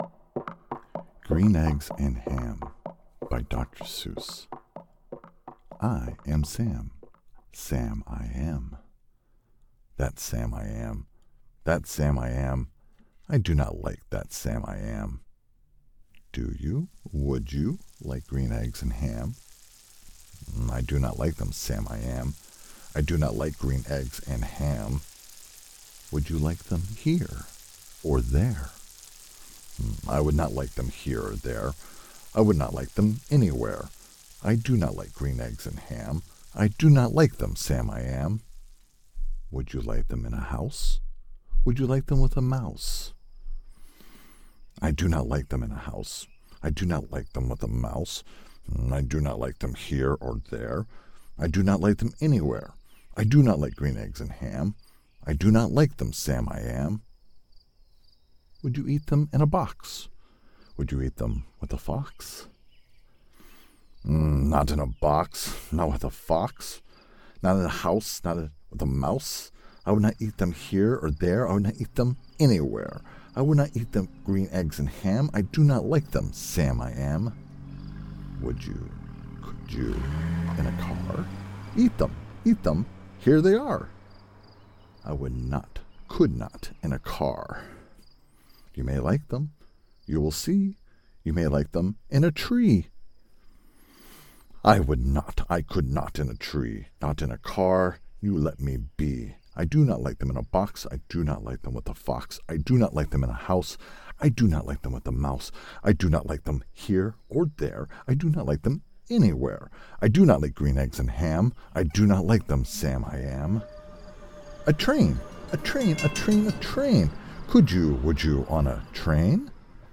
Deep, Calm tones delivering Confident Compassion
Middle Aged
Green Eggs and Ham by Doctor Seuss with Soundtrack.mp3